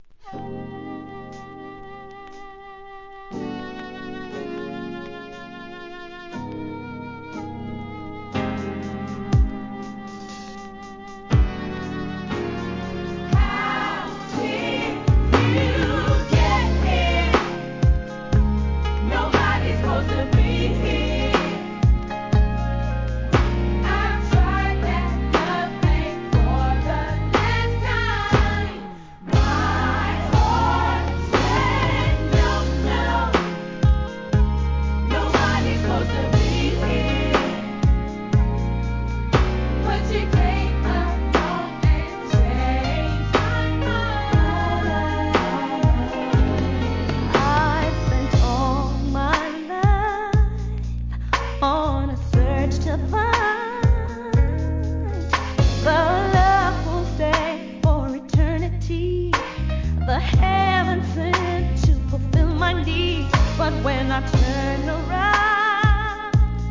HIP HOP/R&B
GOODバラード!!